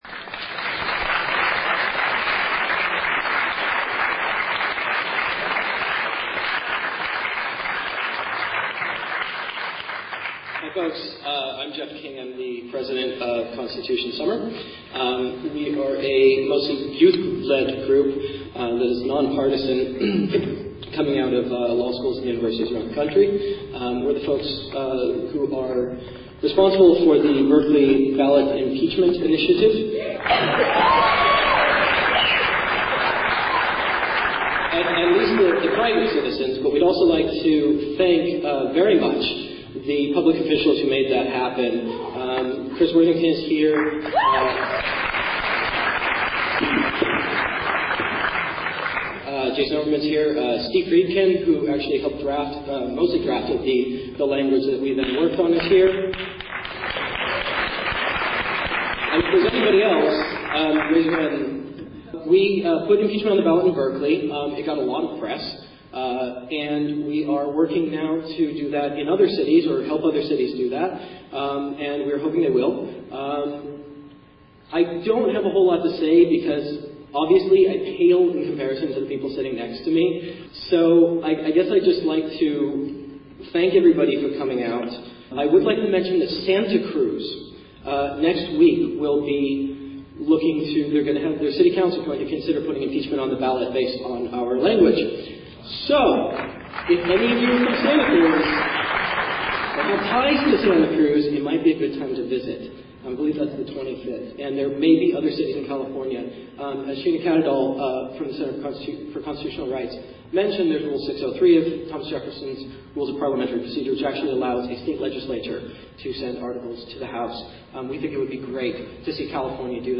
July 19, 2006, Oakland, California Presentation of Constitution Summer's Four Key Crimes: 1) Illegal domestic spying in violation of the Foreign Intelligence Surveillance Act and the 4th Amendment; 2) Misleading the country into a war of aggression in Iraq based on fraudulent claims; 3) Indefinite detention, extraordinary rendition, and torture; 4) Abuse of executive authority and subversion of the Constitution.
Constitution Summer Rally for Impeachment